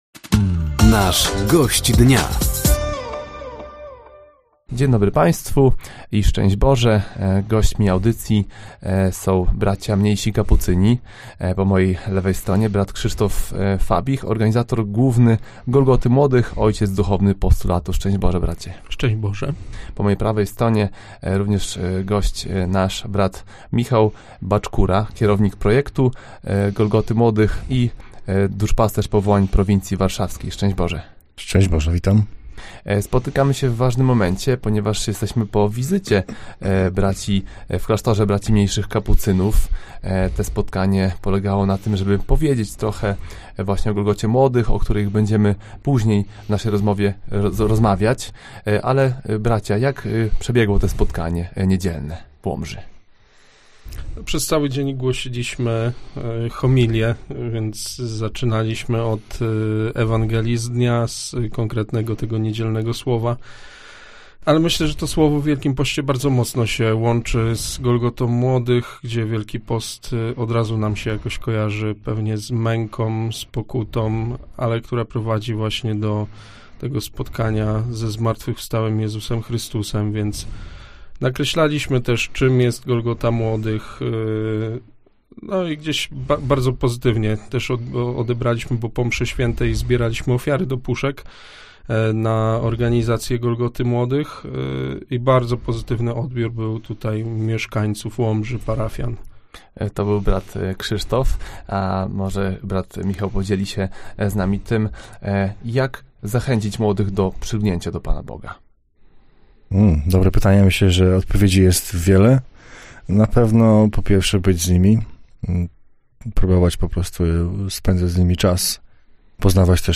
Pozostałe audycje z cyklu “Gość Dnia” Radia Nadzieja: